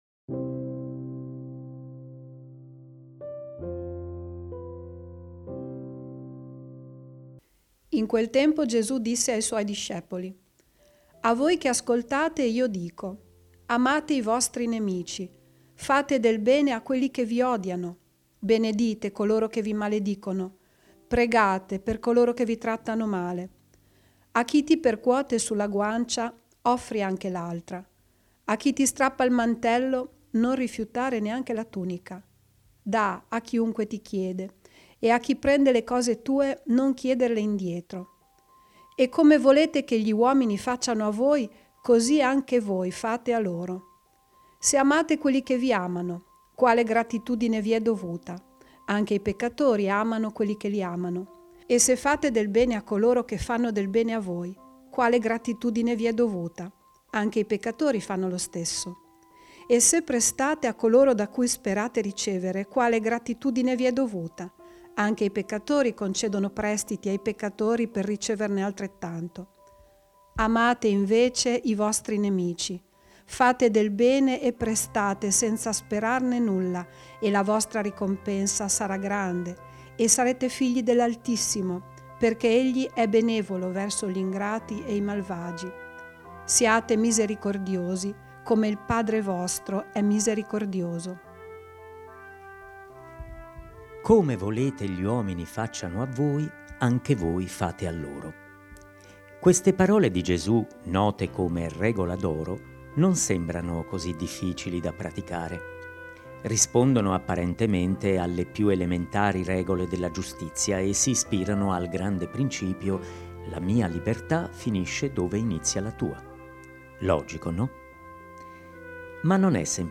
Vangelo Clip 15 Febbraio 2022 Domenica 20 Febbraio 2022 – VII Domenica del Tempo Ordinario – Anno C "Amate i vostri nemici" La riflessione guidata dall'arcivescovo di Modena-Nonantola e vescovo di Carpi don Erio Castellucci sul Vangelo di Luca (Lc 6, 27-38) nella settima domenica del Tempo Ordinario.